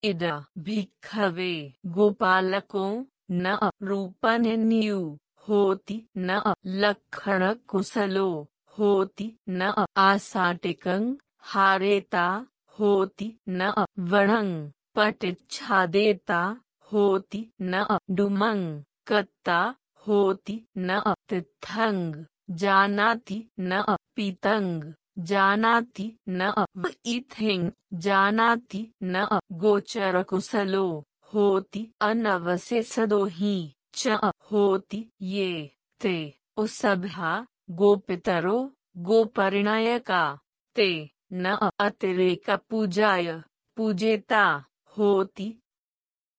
We’re currently addressing Voice Pali pronunciation fixes for version 2.3. Our goal is not to have perfect Pali pronunciation, which is a bit out of reach for current AI.
For example, here is an example where Aditi hiccups unintelligibly on vīthiṃ:
We are using Amazon Web Services Polly API to correct Aditi’s speech.